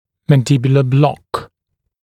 [ˌmæn’dɪbjulə blɔk][ˌмэн’дибйулэ блок]блокада нижней челюсти с помощью анестетика